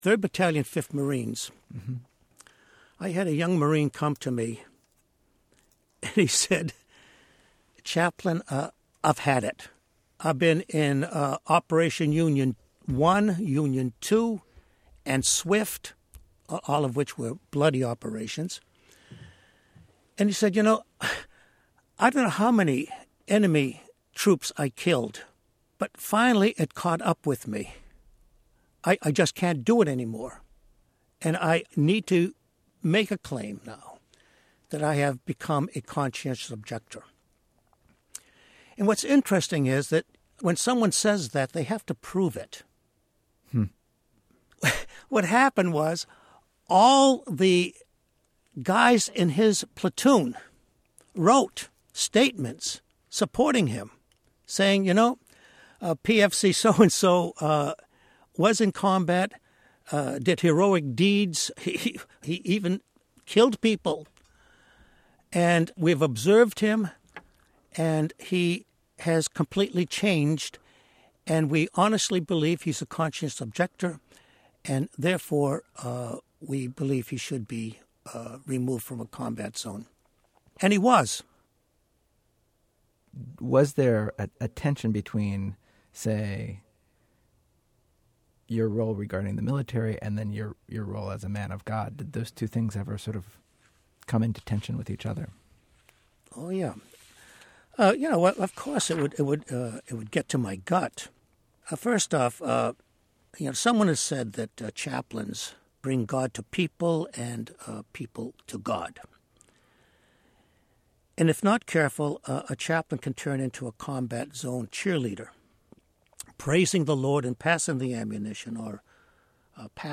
We weren't able to squeeze this excellent interview into Commandment Six of our Ten Commandments show, but you can listen to it here.